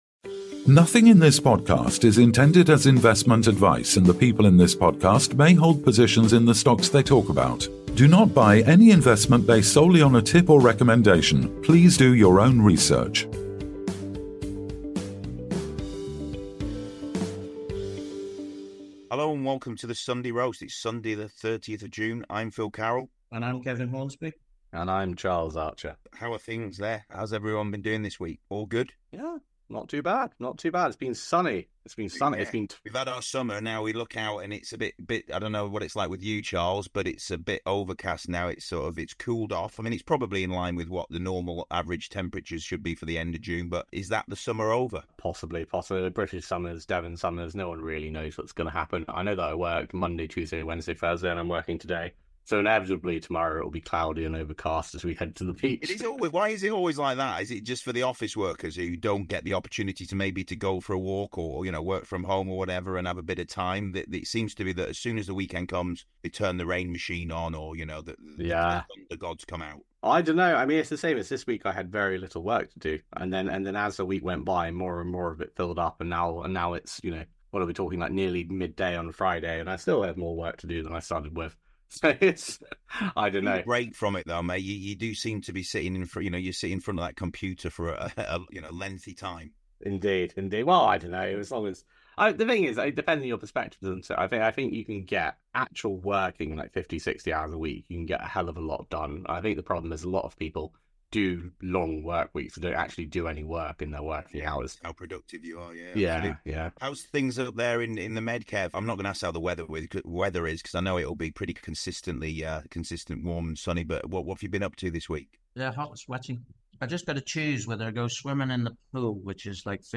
They discuss the standout performers like Guardian Metals and Incanthera, and explore the challenges faced by underperformers such as Conroy Gold and Technology Minerals. The conversation also covers the latest developments and future potential of companies like Jubilee Metals, Sovereign Metals, and Power Metal Resources.